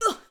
SFX_Battle_Vesna_Attack_05.wav